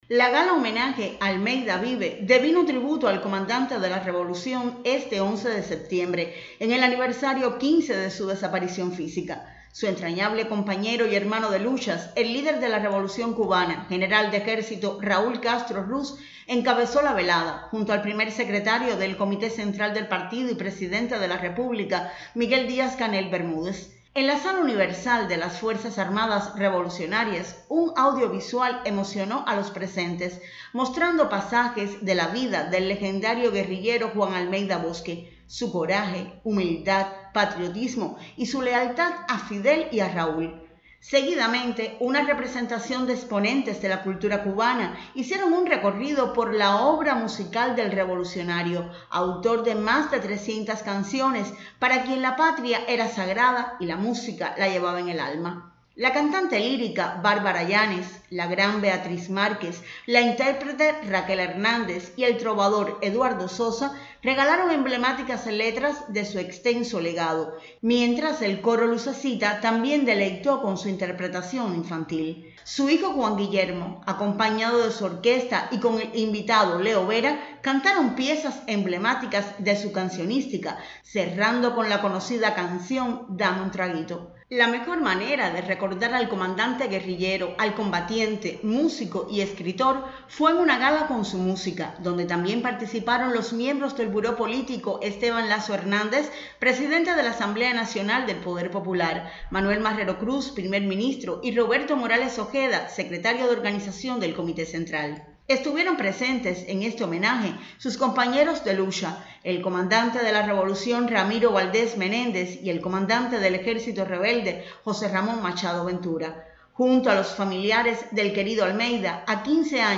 El General de Ejército Raúl Castro Ruz, líder de la Revolución Cubana, y el Primer Secretario del Partido y Presidente de la República, Miguel Díaz-Canel Bermúdez, encabezaron la gala de homenaje al Comandante de la Revolución Juan Almeida Bosque, por el aniversario 15 de su desaparición física